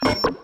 UIBeep_Button Close.wav